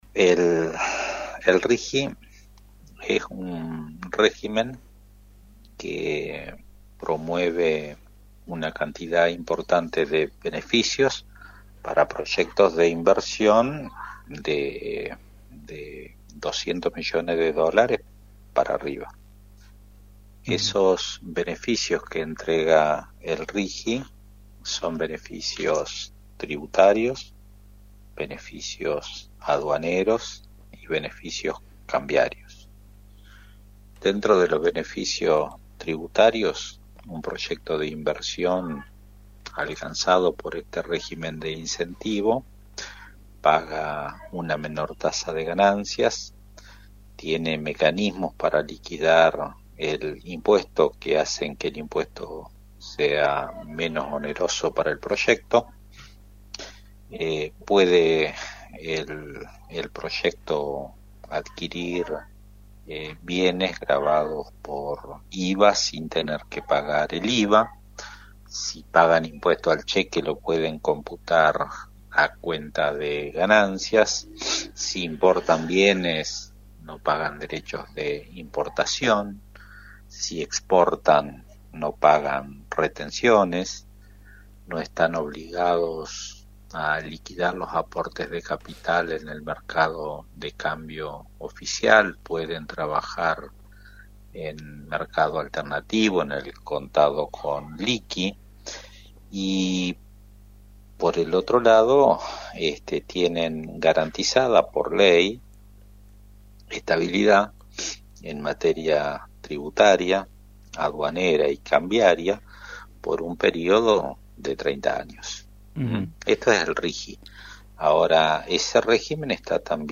El Senador Provincial, Víctor Horacio Sanzberro, explicó en Radio Victoria el imapcto que tendrán estos nuevos regímenes en Entre Rios: